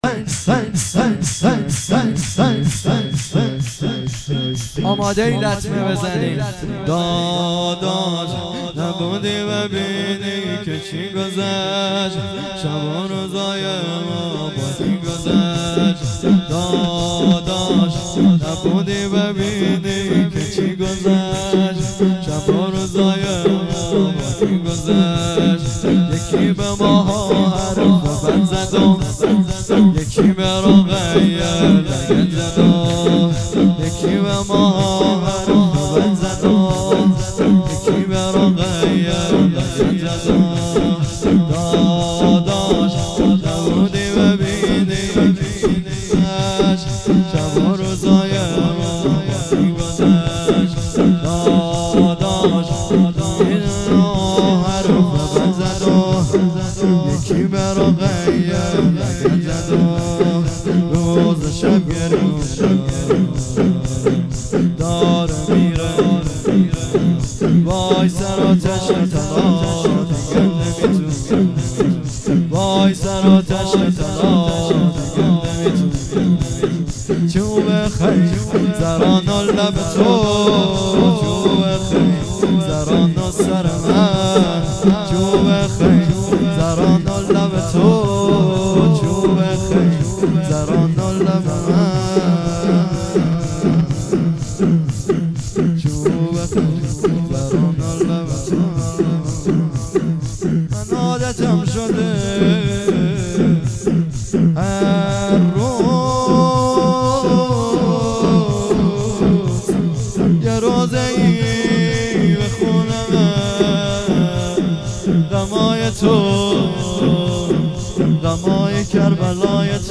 شورلطمه زنی
مراسم شهادت حضرت رقیه سلام الله علیها